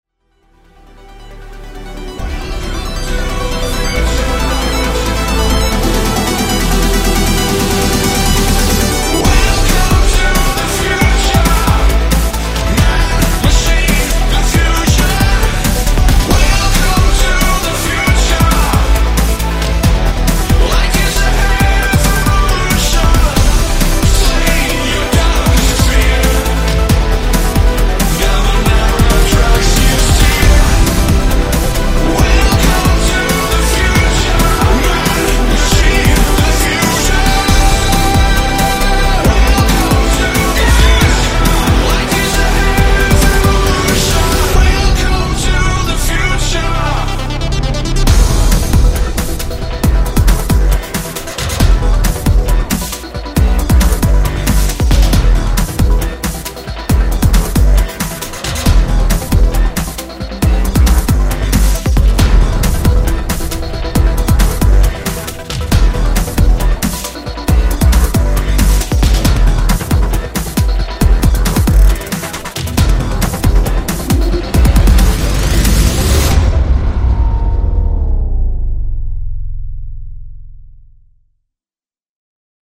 • Качество: 128, Stereo
Хороший трэк из игры